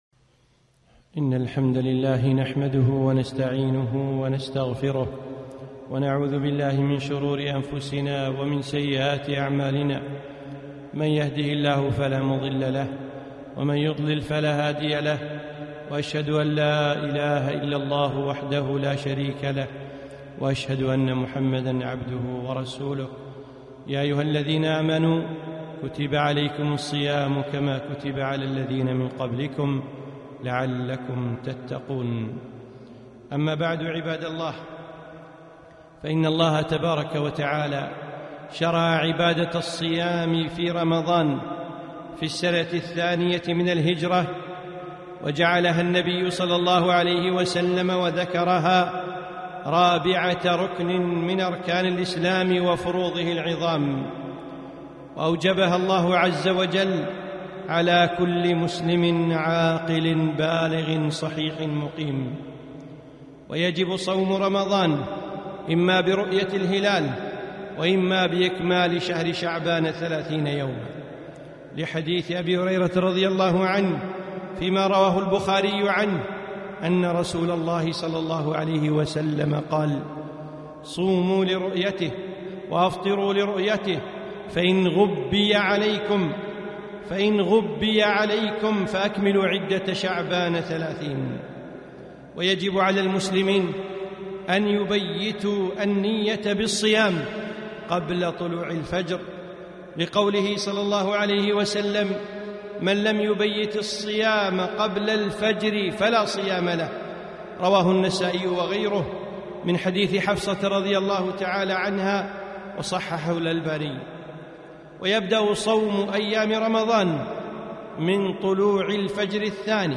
خطبة - من أحكام الصيام